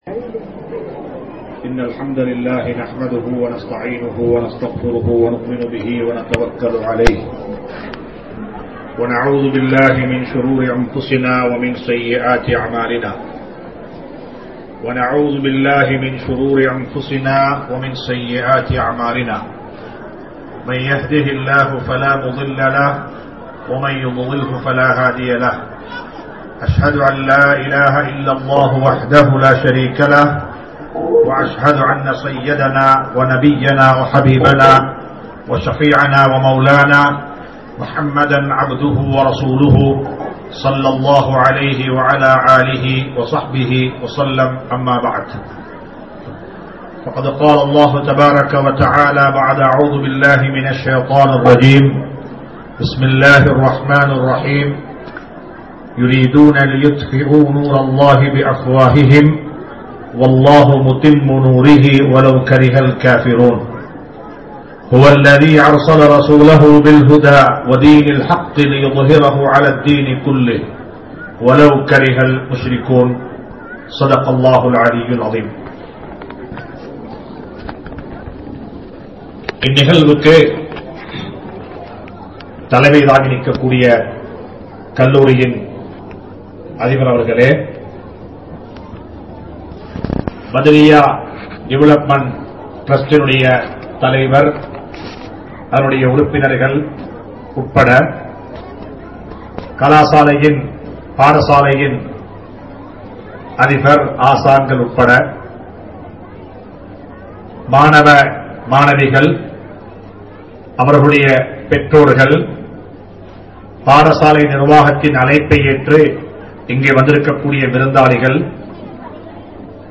Ungalin Kadamaihal (உங்களின் கடமைகள்) | Audio Bayans | All Ceylon Muslim Youth Community | Addalaichenai